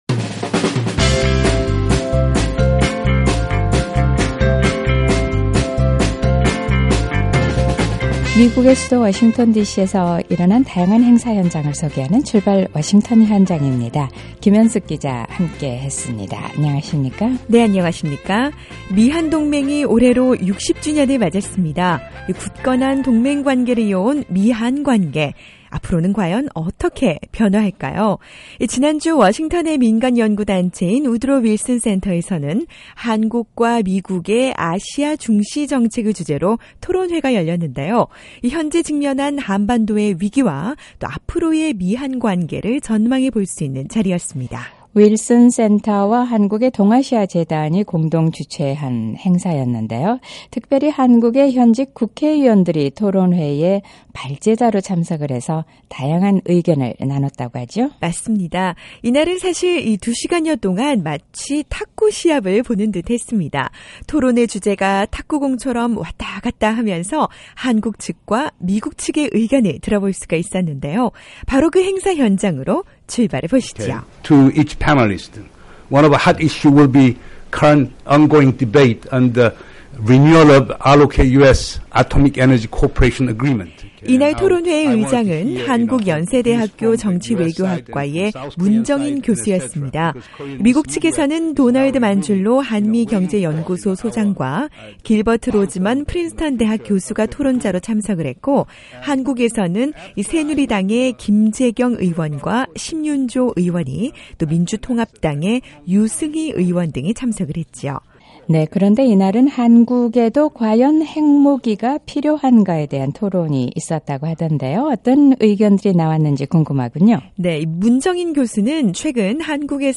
이런 상황에서 한국의 현직 국회의원들과 미국의 전문가들이 한자리에 모여 북핵문제와 미한동맹 관계를 놓고 열띤 토론의 장을 열었습니다. 우드로윌슨센터에서 열린 ‘한국과 미국의 아시아 중시정책’을 주제로 한 토론회였는데요, 열띤 토론이 오갔던 현장으로 출발해보시죠!